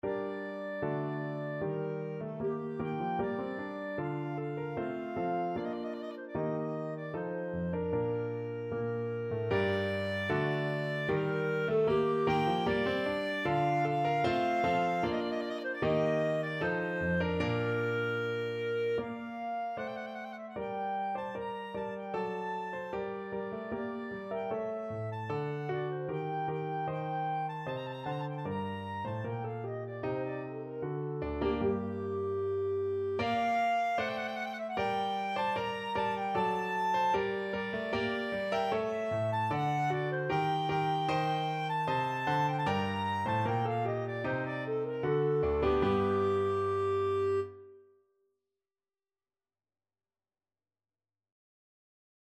Free Sheet music for Clarinet
G minor (Sounding Pitch) A minor (Clarinet in Bb) (View more G minor Music for Clarinet )
2/2 (View more 2/2 Music)
Steadily =c.76
Clarinet  (View more Easy Clarinet Music)
Classical (View more Classical Clarinet Music)